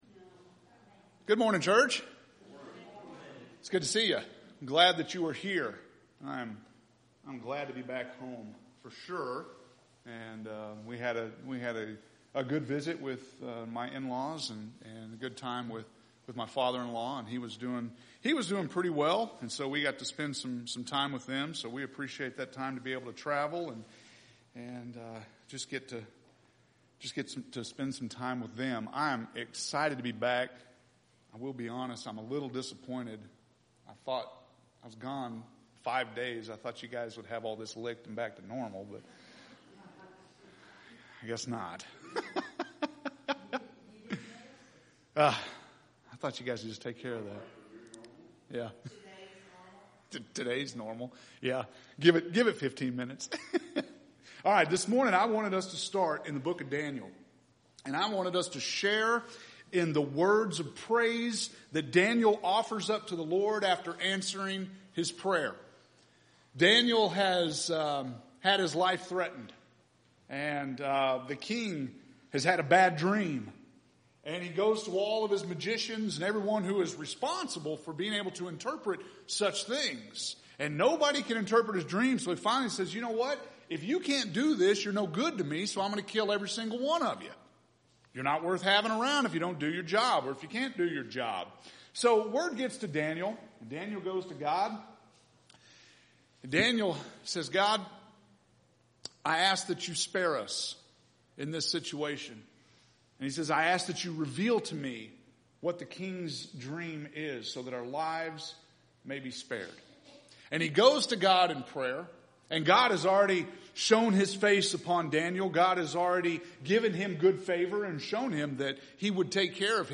July 12th – Sermons